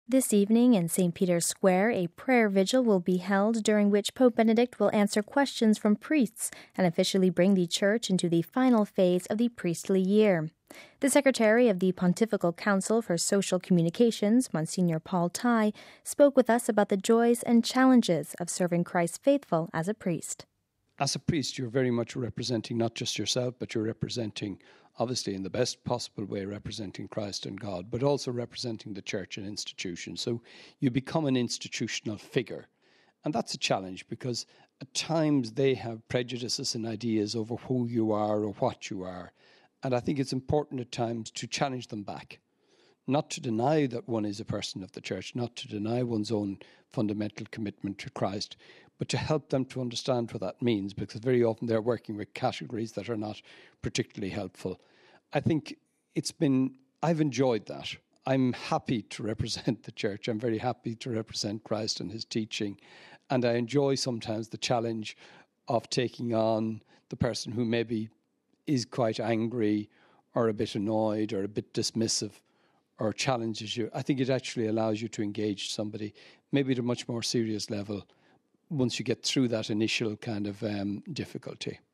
The Secretary of the Pontifical Council for Social Communications, Msgr. Paul Tighe, spoke with us about the joys and challenges of serving Christ’s faithful as a priest…